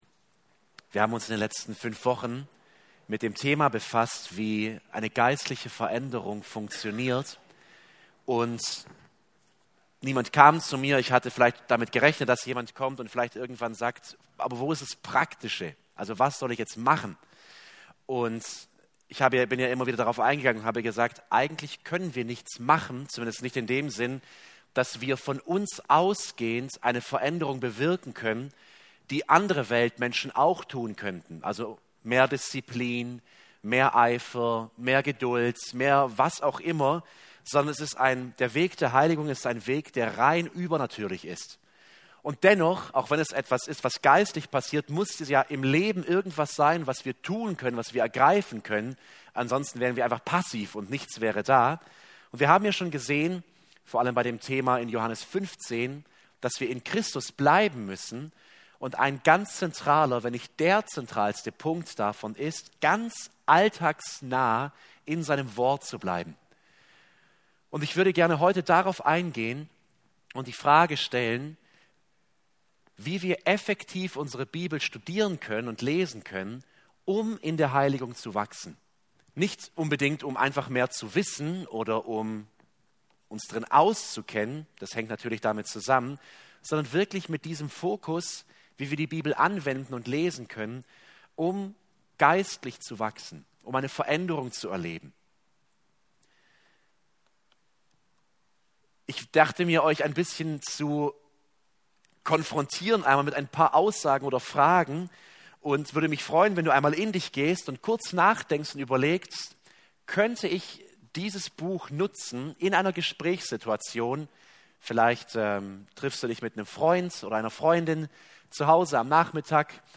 Hier findet ihr die Bibelstunden der Freikirchlichen Gemeinde Böbingen e.V.